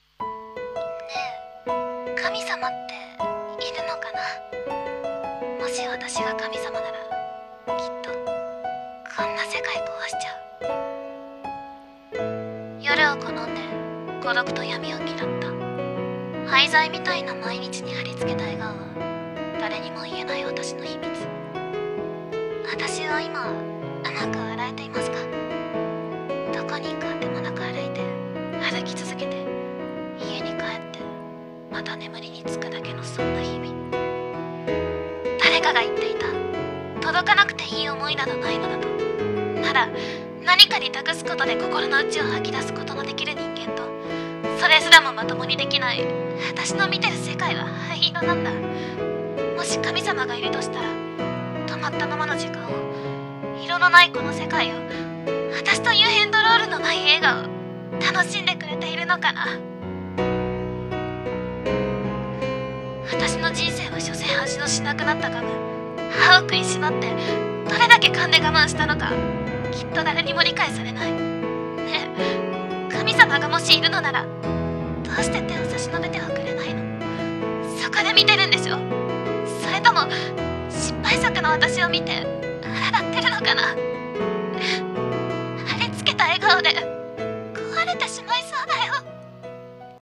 】【声劇】 色彩とモノローグ 読み手